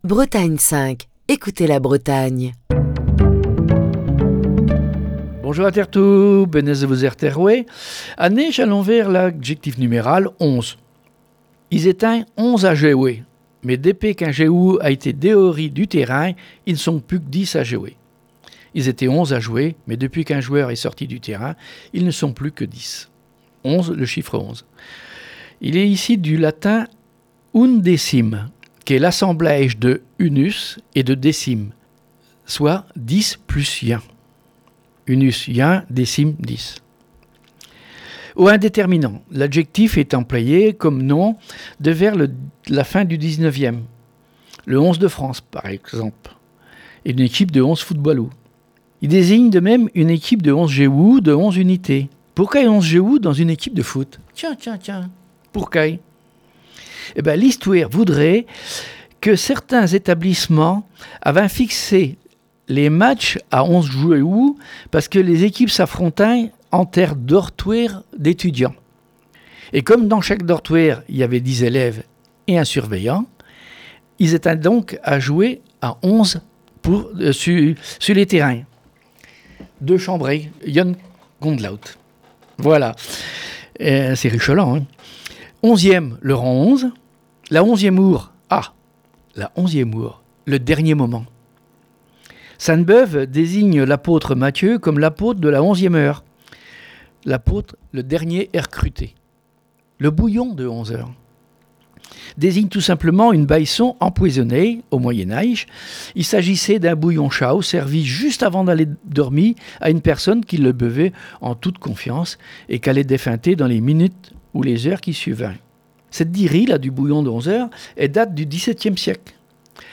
Chronique du 21 septembre 2021.